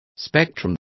Complete with pronunciation of the translation of spectra.